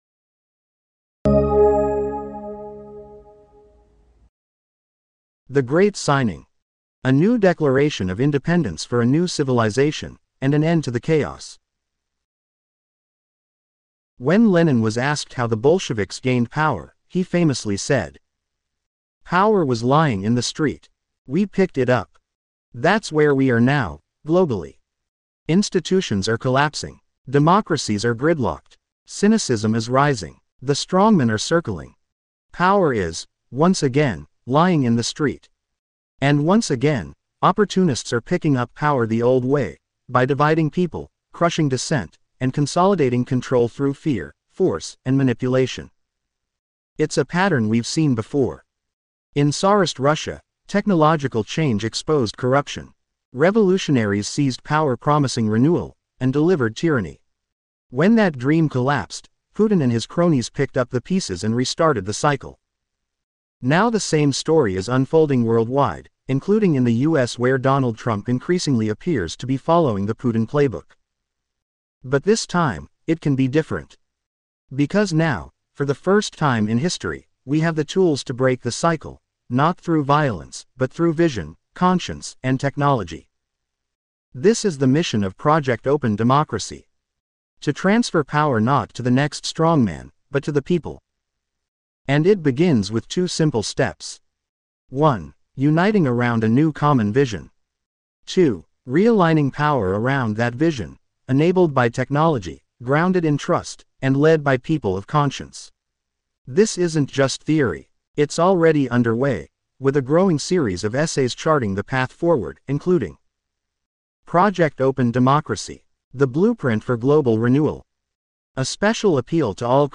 Check out our AI “chat” for an overview